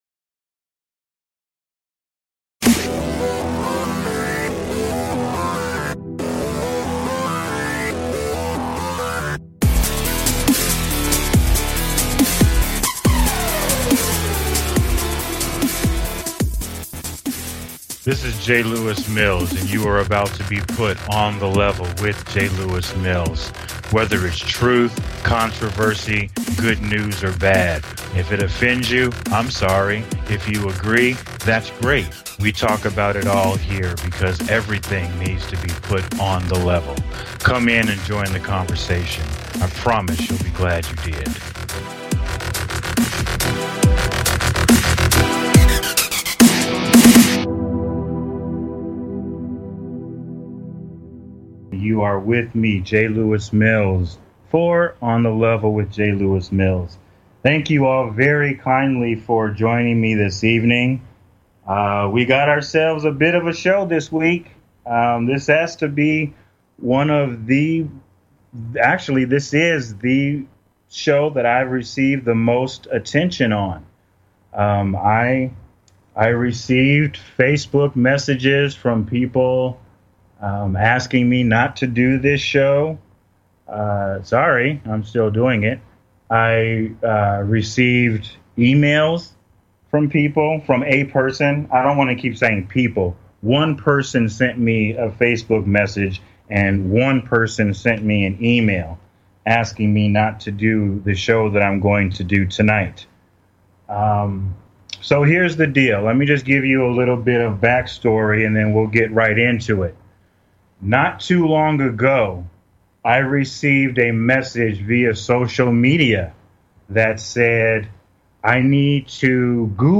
Talk Show Episode, Audio Podcast, On The Level and Insight and accountability on , show guests , about Accountability,licensed profession,nursing profession, categorized as Health & Lifestyle,Kids & Family,Medicine,News,Self Help,Variety
Is it ok to step outside the scope of your licensed profession? Join me tonight for a very serious conversation.